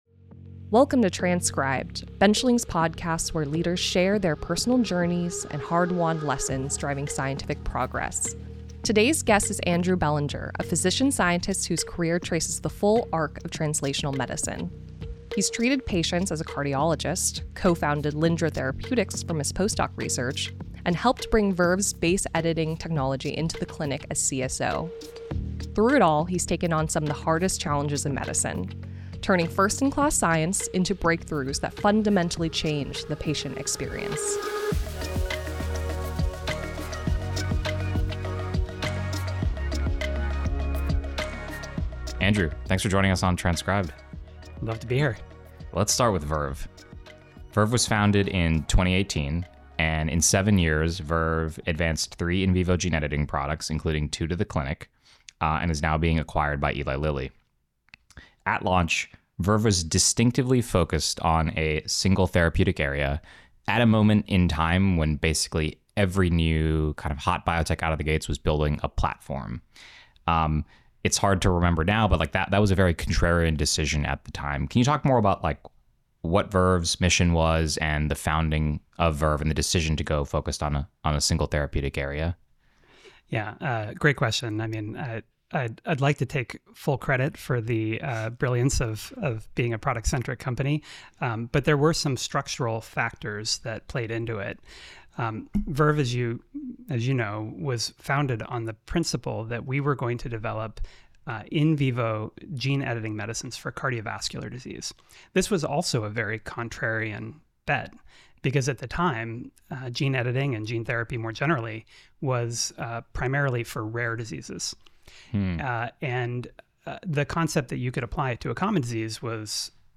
* Editor's note: The interview was recorded following the announcement of Verve's acquisition by Lilly, which has since been completed.